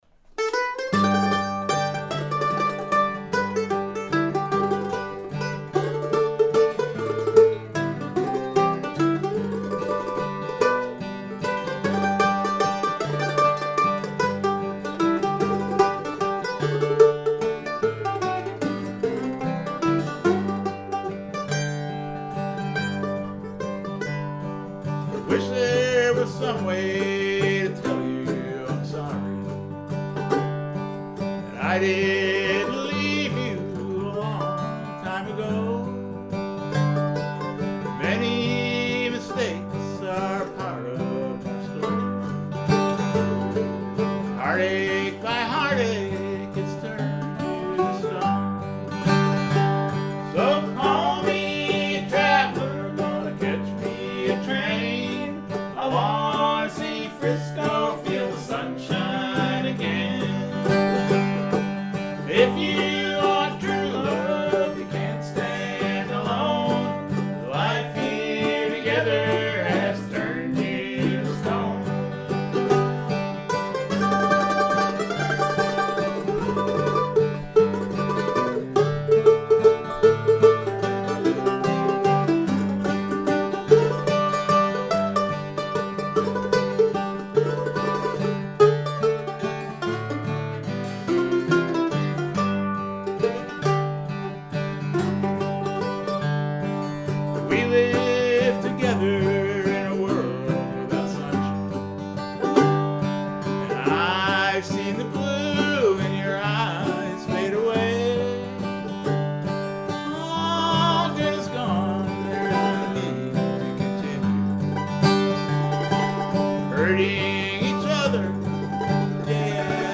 Rehearsal